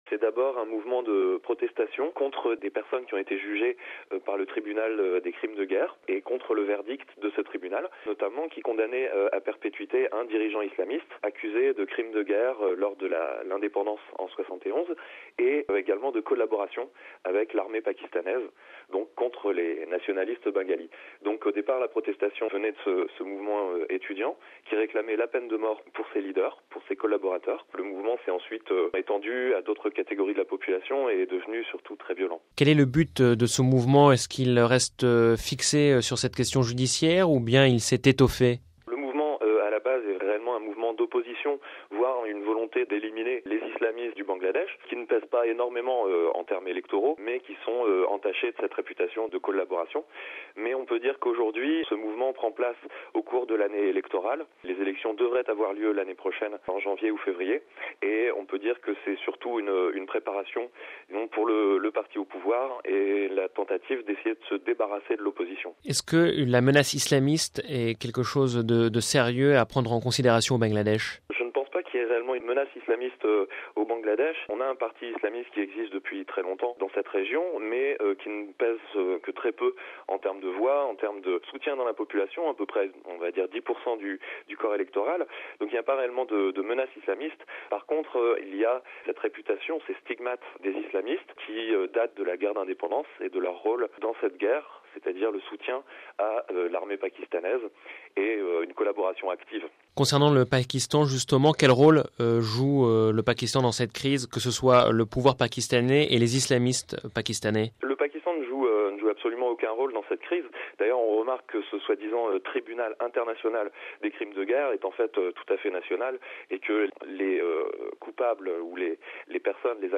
Le politologue et spécialiste du Bangladesh